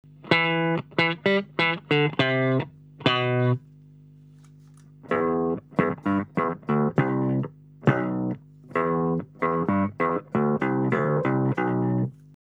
-Le pitch shifter : Cette fois, il s’agit de modifier la hauteur du signal. Vous pouvez ainsi régler l’effet pour jouer à une (ou plusieurs) octaves plus haut ou plus bas que ne devrait l’être le son de votre guitare.
Whammy-1oct.mp3